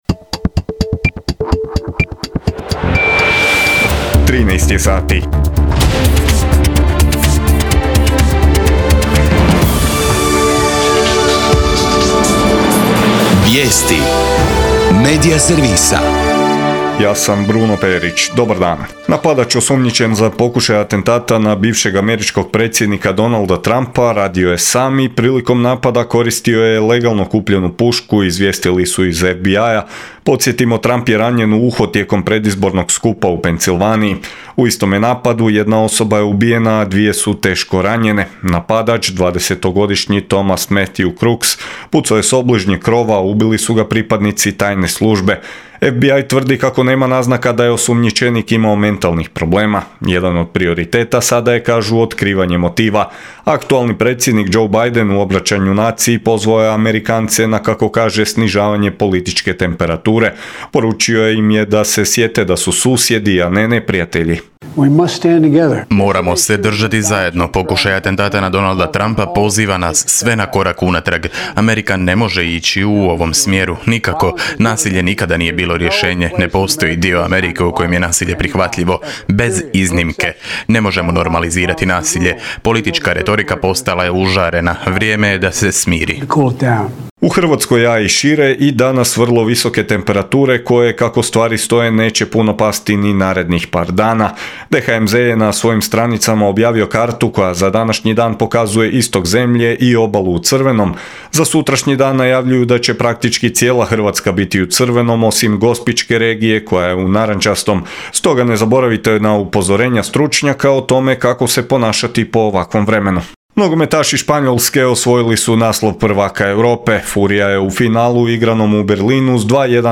VIJESTI U 13